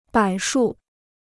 柏树 (bǎi shù): cyprès.